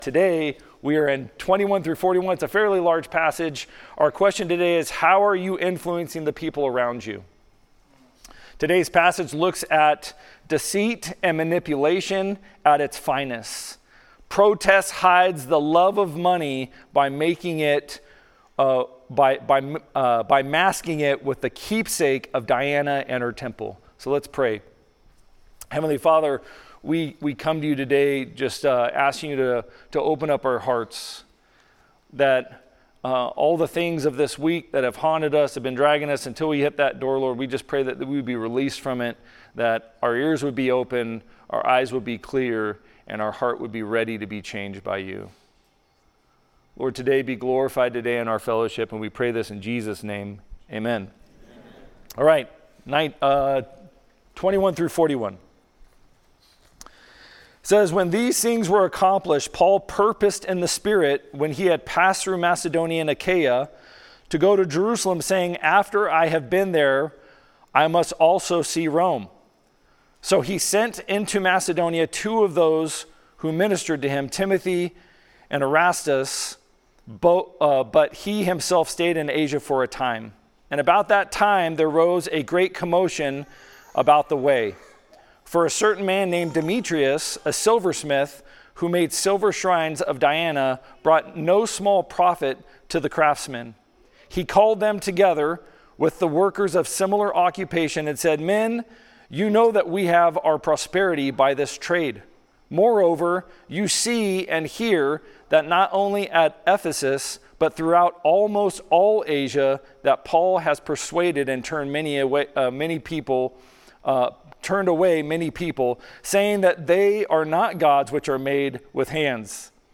Sermons | Unbound Fellowship